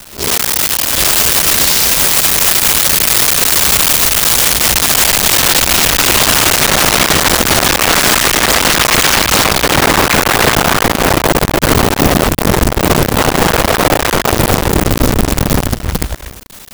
Flyby 1
Flyby_1.wav